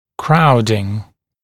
[‘kraudɪŋ][‘краудин]скученность